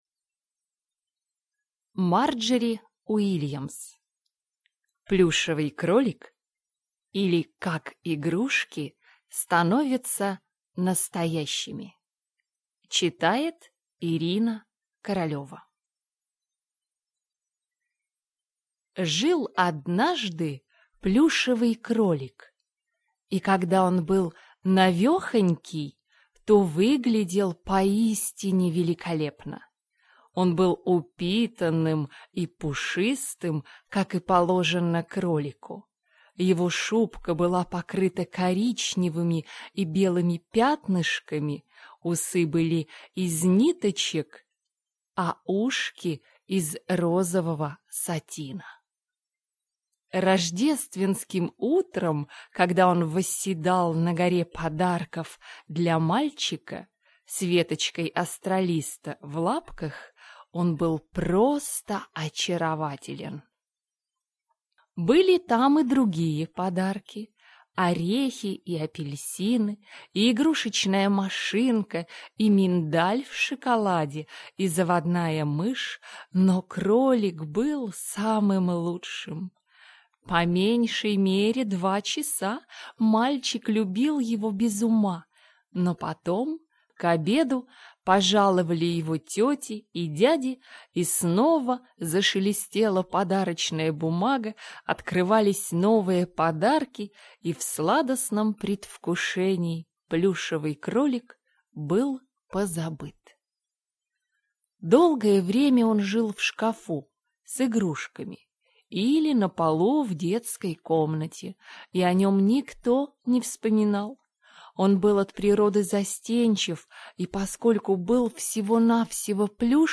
Аудиокнига Плюшевый Кролик | Библиотека аудиокниг